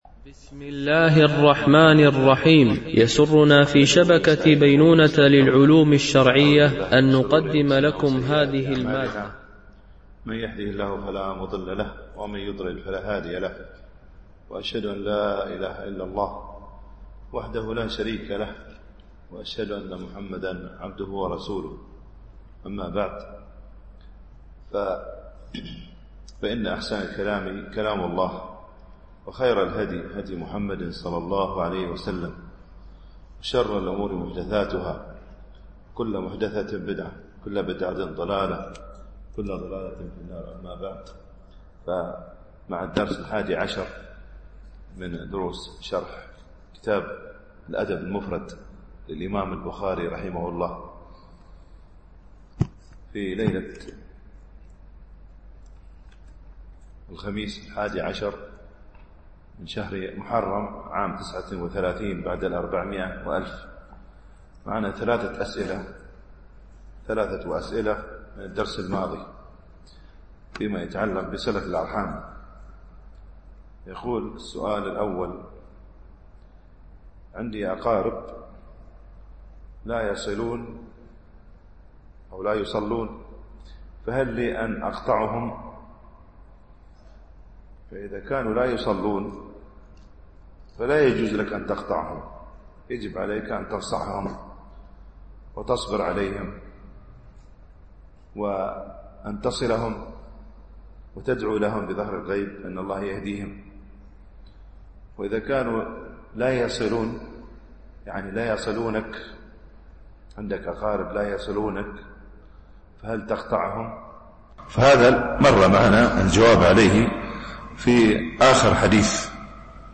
شرح الأدب المفرد للبخاري ـ الدرس 11 ( الحديث 53-59 )